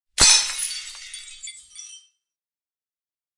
Download Glass Breaking sound sound effect for free.
Glass Breaking Sound